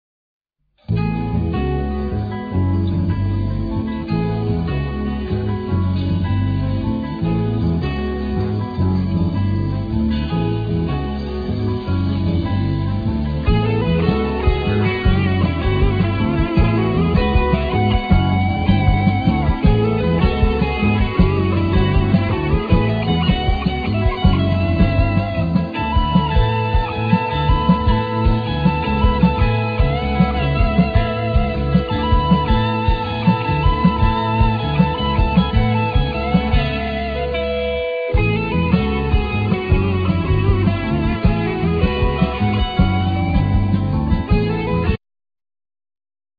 Keyboards
Synthesizers
Flute
Guiatr
Bass
Drums